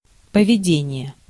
Ääntäminen
Ääntäminen France: IPA: /kɔ̃.pɔʁ.tə.mɑ̃/ Haettu sana löytyi näillä lähdekielillä: ranska Käännös Ääninäyte 1. поведение {n} (povedenije) 2. поведе́ние {n} (povedénije) Suku: m .